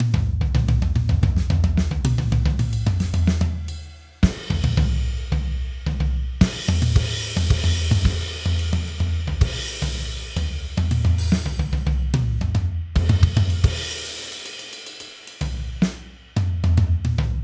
On transcribing a drum audio file (as added below), a pitch against time visualization was created.
We experimented with piano and drums transcription using the customized networks as suggested in the papers and observed the output (pitch vs time and transcription) on manually created audio files using the GarageBand software.
Drums.wav